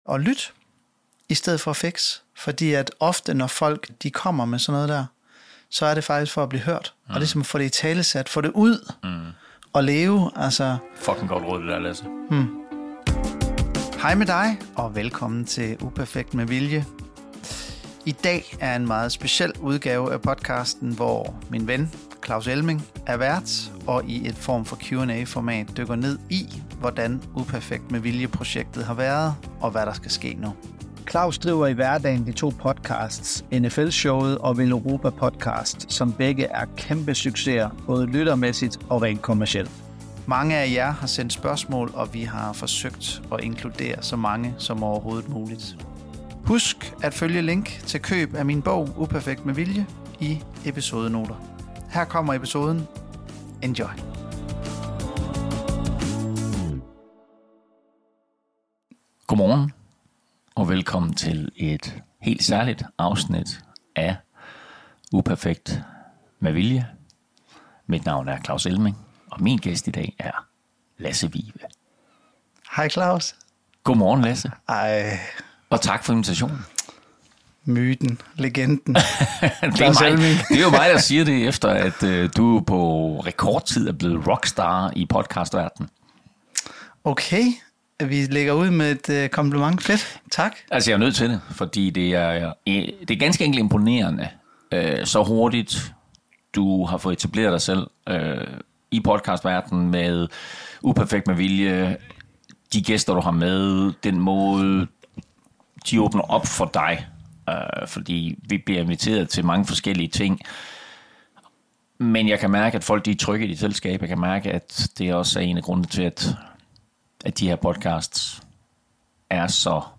En super dag i studiet.